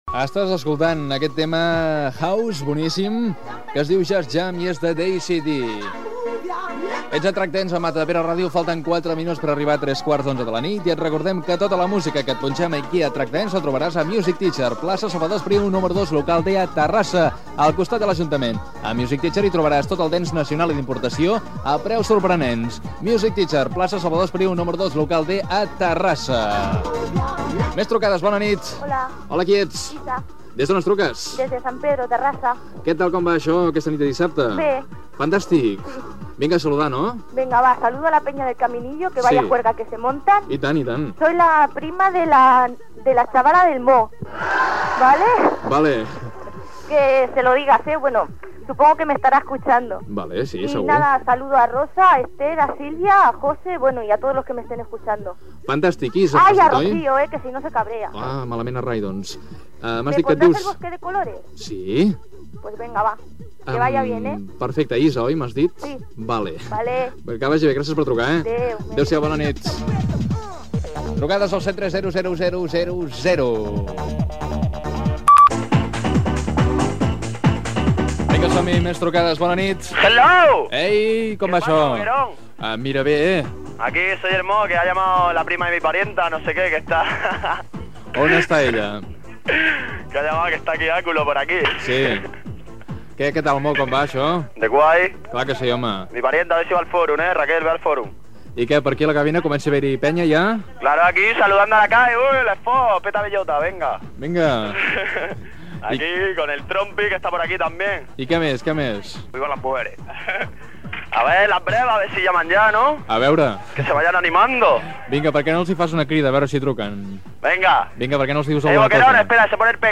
Trucades telefòniques dels oients.
Musical